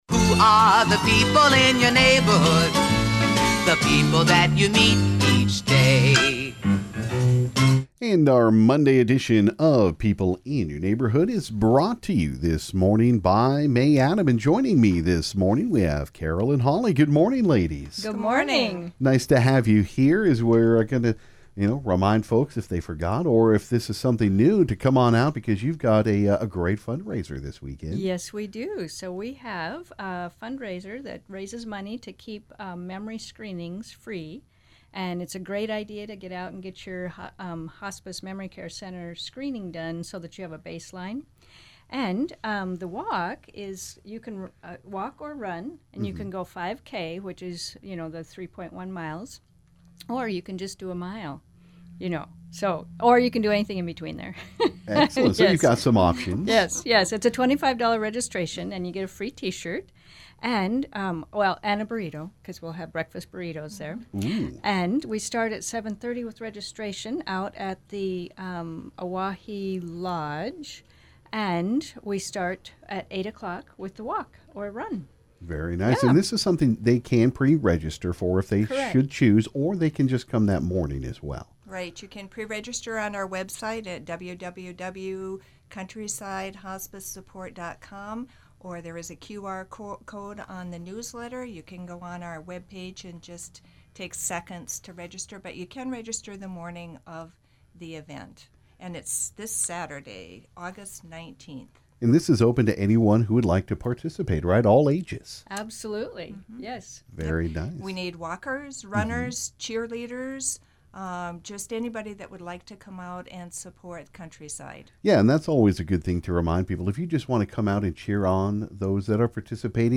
This morning on KGFX for People In Your Neighborhood we had a couple of guests from Countryside Hospice Memory Center.